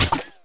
minig_dryfire_01.wav